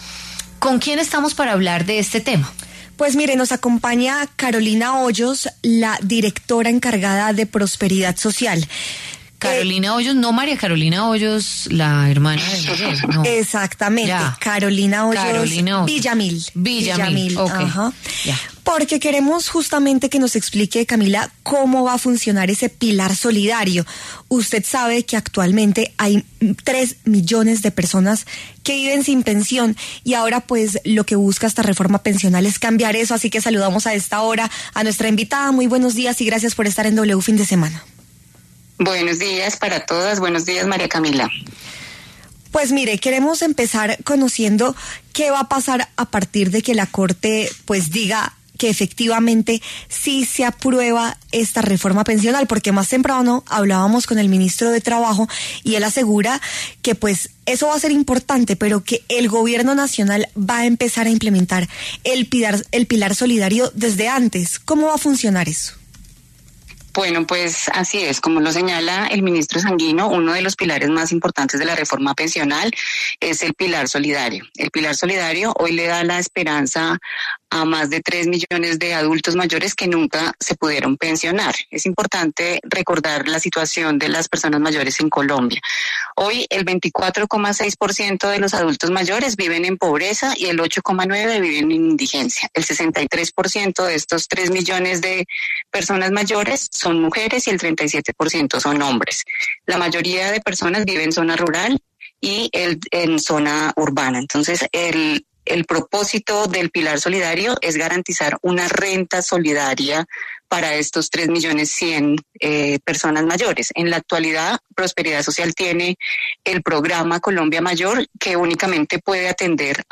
La directora encargada de Prosperidad Social, Carolina Hoyos Villamil, pasó por los micrófonos de La W para explicar en detalle en qué consiste el pilar solidario que hace parte de la reforma pensional.